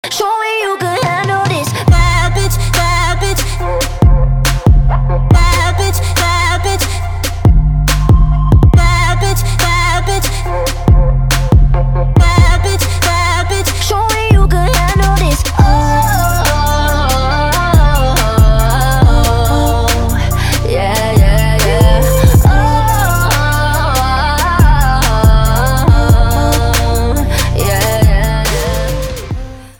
• Качество: 320, Stereo
женский вокал
Хип-хоп
RnB
Bass